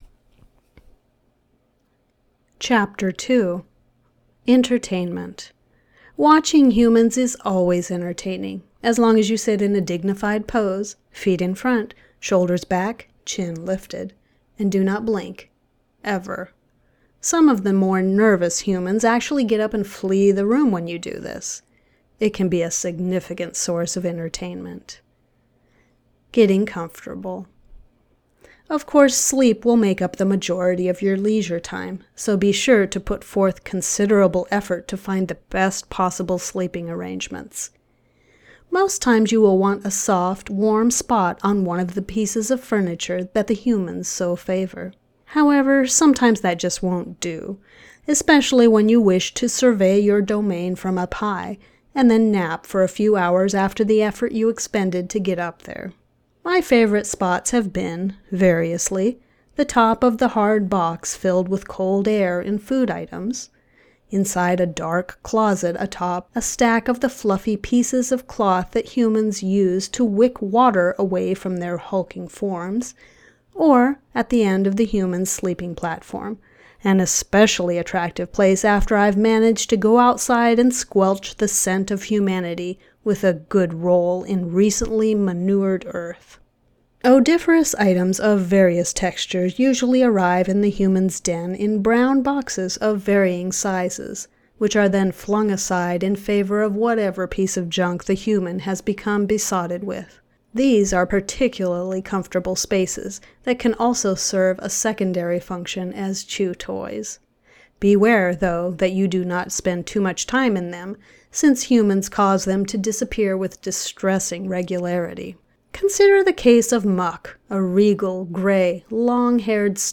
Manipulating Decibels - Page 3 - Audiobook Production - Audacity Forum
I just gently suppressed it a little and left your clothing movements and expressions alone.
At normal listening volume, the background noise is almost missing, volume is normal, expression is crisp and clear, and the peaks dance around -3dB.